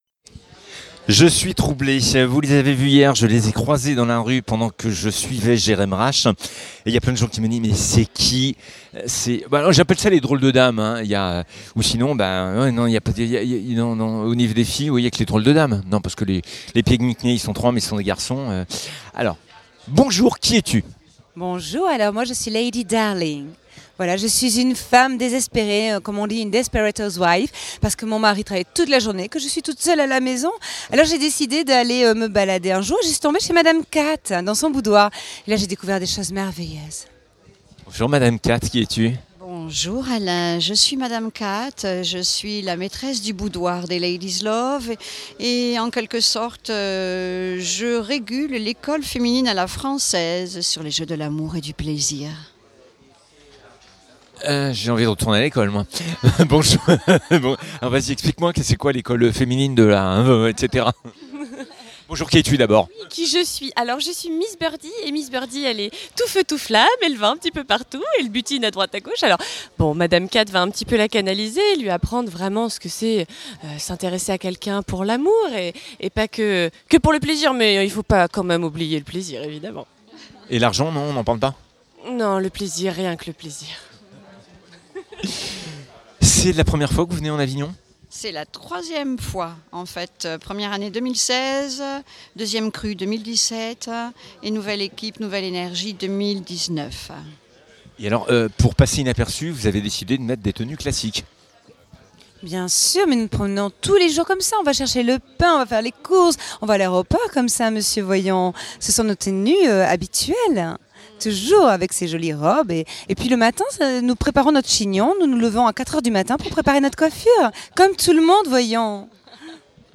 Le théâtral Musical Comico coquin à succomber de plaisir